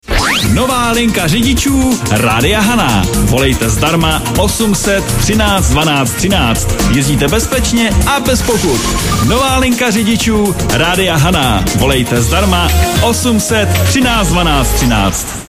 up-dopravni-linka-ridicu-radio-hana-nova.mp3